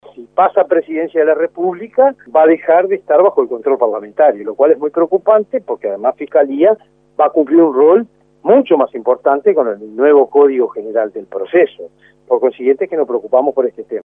En conversación con 810 VIVO, Ithurralde dijo que en la reunión del lunes, Vázquez les había planteado una nueva posición institucional acerca de las fiscalías, pero lo que se había hablado hasta el momento se trataba de darle más autonomía.